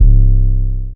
MexikoDro808.wav